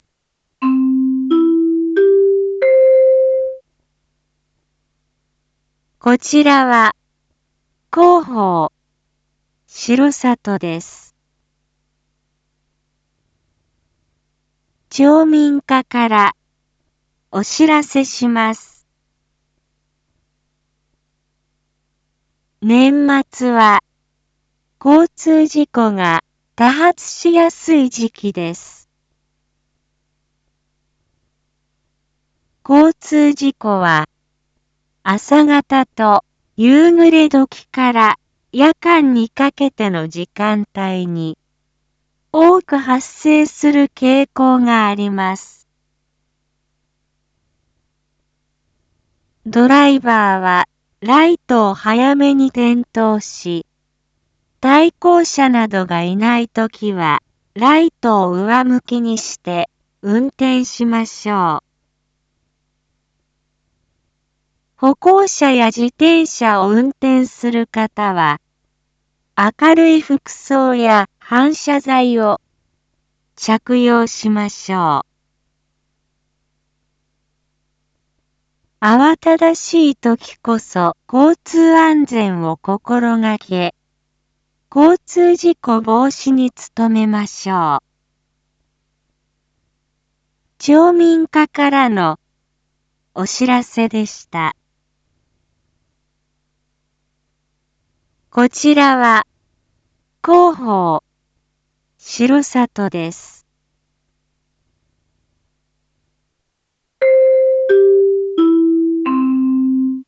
Back Home 一般放送情報 音声放送 再生 一般放送情報 登録日時：2023-12-22 19:01:51 タイトル：年末の交通事故防止について インフォメーション：こちらは、広報しろさとです。